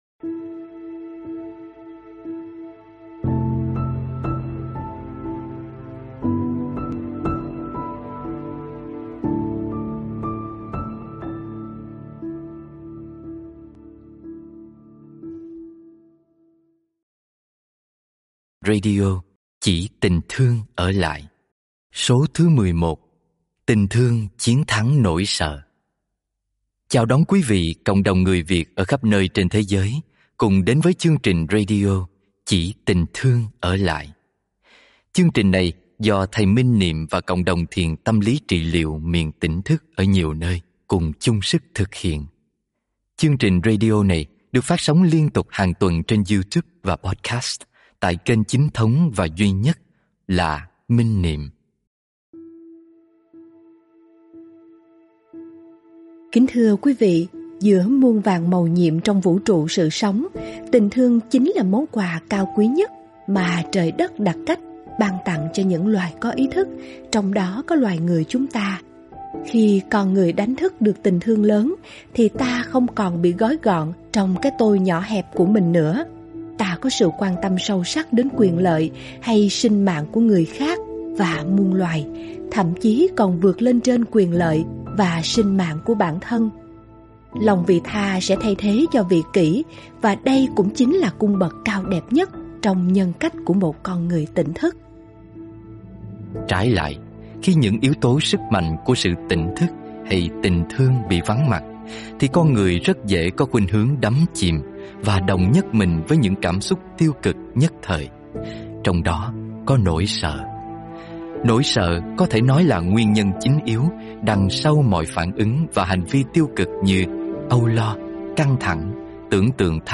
Radio Chỉ tình thương ở lại – Số 11: Tình thương chiến thắng nỗi sợ do thiền sư Thích Minh Niệm giảng